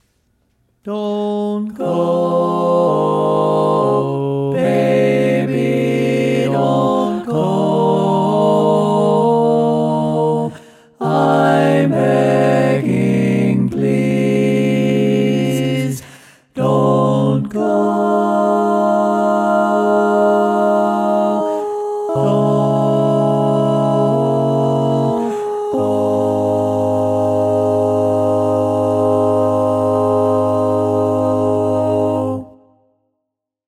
Key written in: A♭ Major
How many parts: 4
Type: Barbershop
All Parts mix: